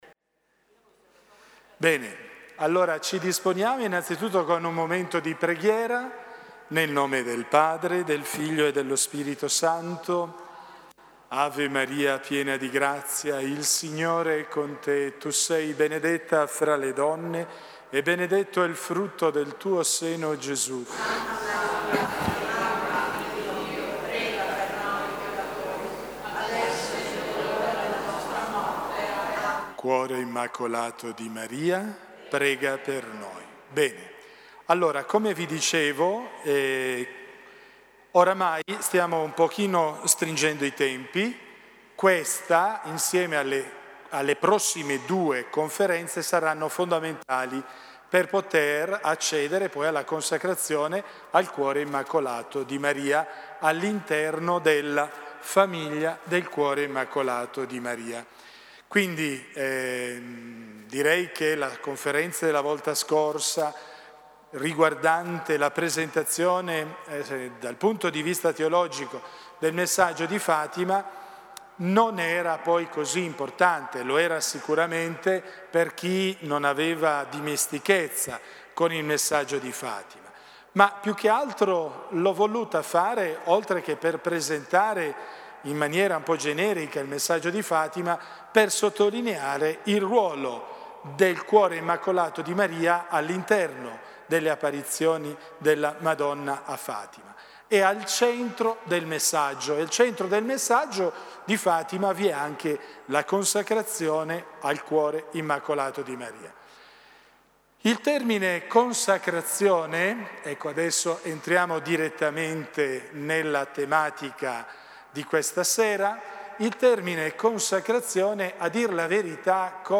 Catechesi mariane – Tempio Nazionale a Maria Madre e Regina – Santuario di Monte Grisa